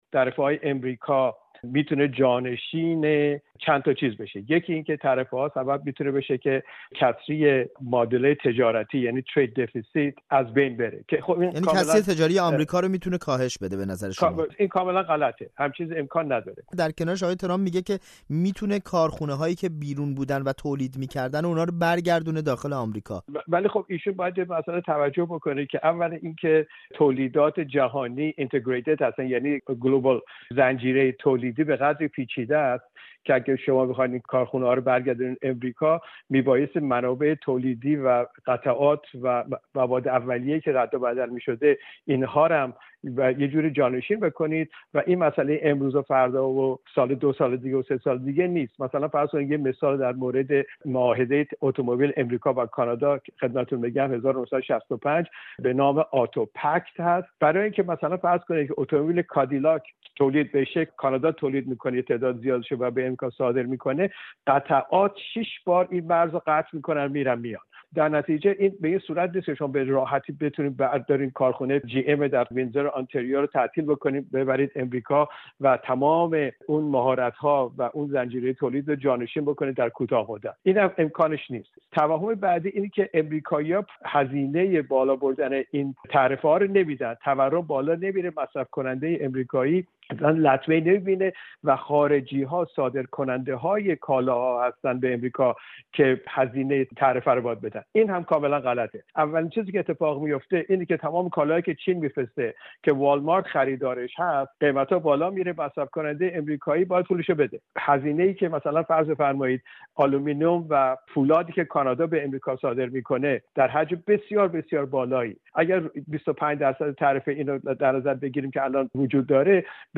در گفت‌وگو با رادیوفردا